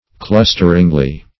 clusteringly - definition of clusteringly - synonyms, pronunciation, spelling from Free Dictionary Search Result for " clusteringly" : The Collaborative International Dictionary of English v.0.48: Clusteringly \Clus"ter*ing*ly\, adv.